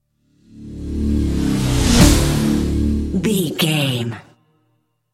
Ionian/Major
Fast
synthesiser
drum machine
Eurodance